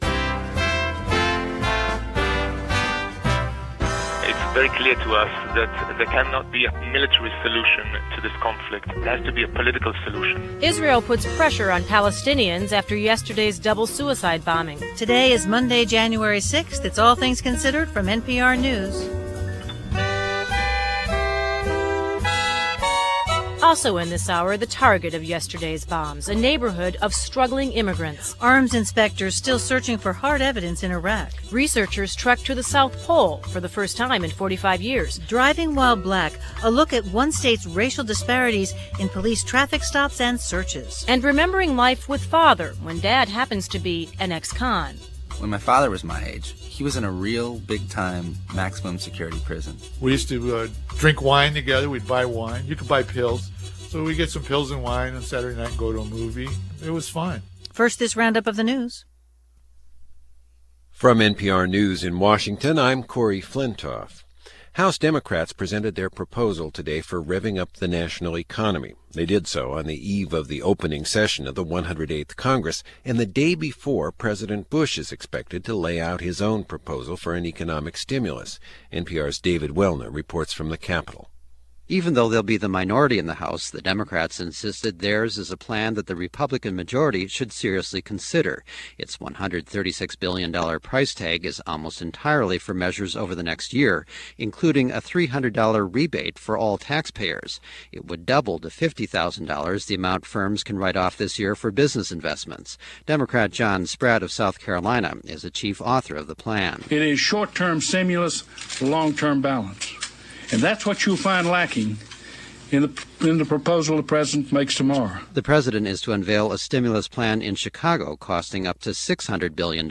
And that’s a small capsule of what happened, this January 6, 2003 as presented by National Public Radio.